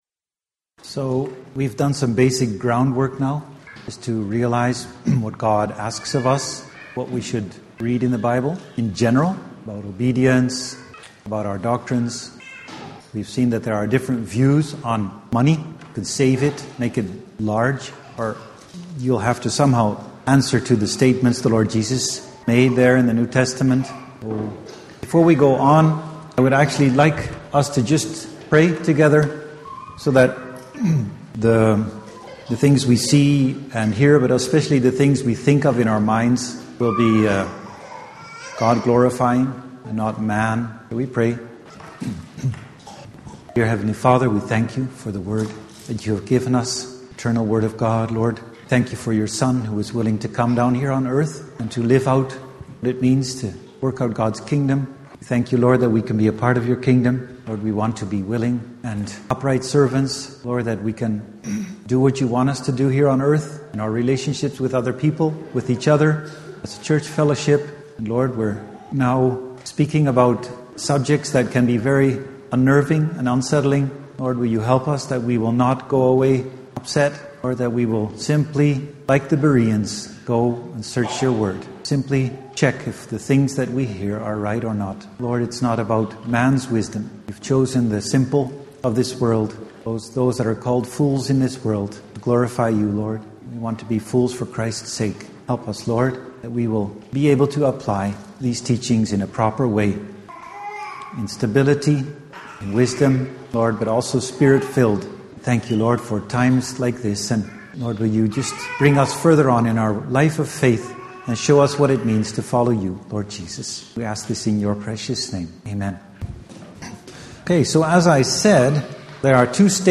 Biblical Finances Seminar Service Type: Sunday Morning %todo_render% « Biblical Finances